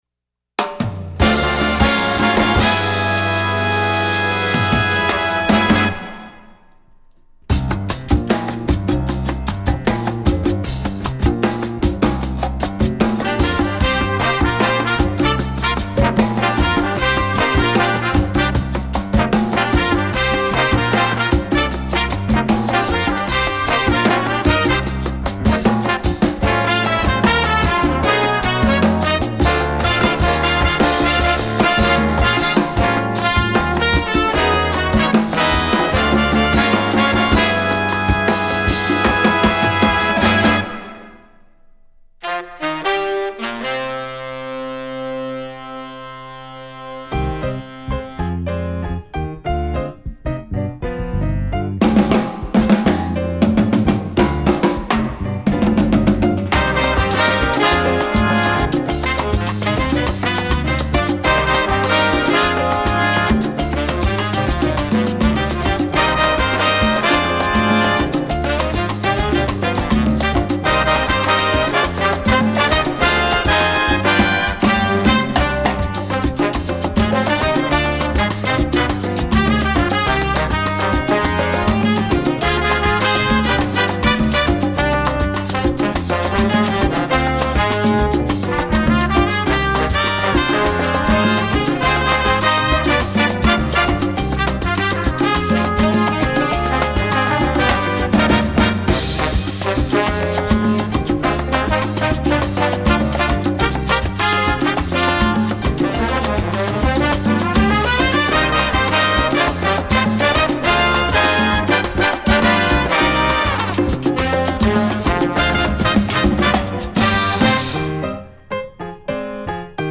Instrumentation: jazz band
jazz, latin